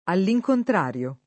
DOP: Dizionario di Ortografia e Pronunzia della lingua italiana
[ all i j kontr # r L o ]